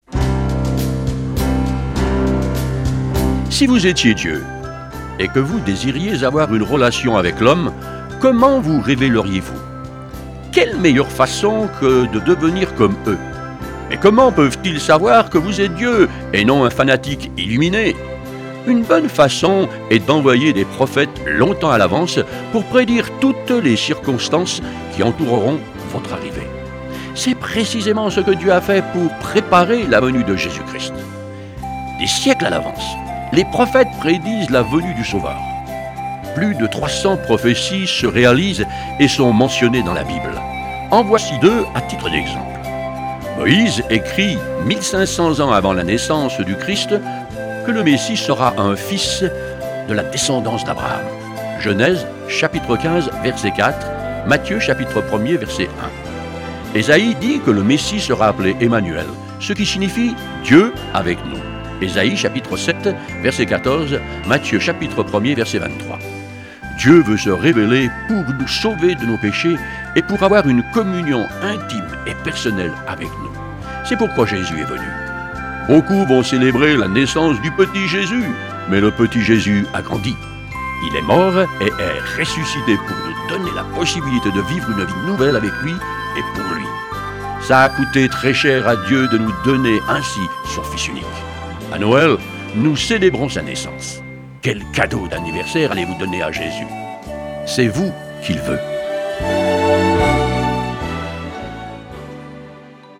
Une série de méditations pour le mois de Décembre